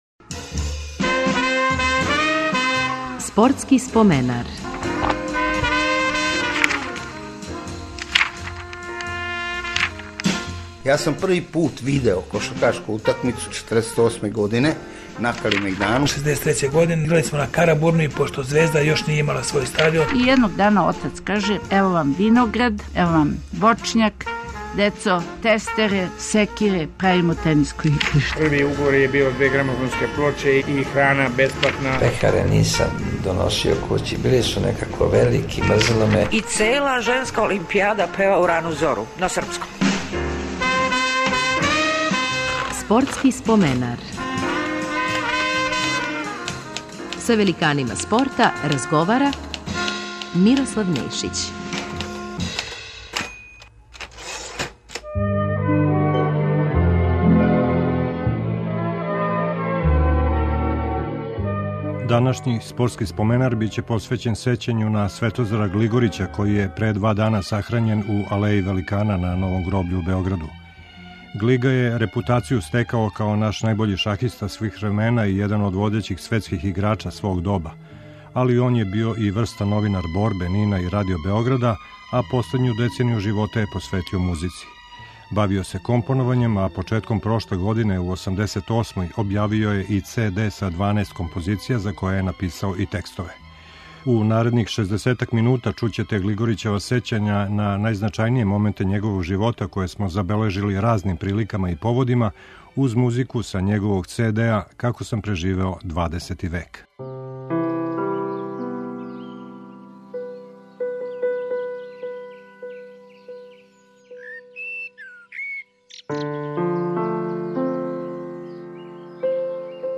Спортски споменар ће бити посвећен сећању на велемајстора Светозара Глигорића који је пре неколико дана преминуо у 90. години. Биће емитовани снимци разговора са њим начињени разним приликама и поводима о његовом детињству, шаховским почецима и врхунским играчким дометима, дугогодишњем пријатељству са Робертом Фишером, новинарском раду у Борби, НИН-у и Радио Београду, бављењу музиком...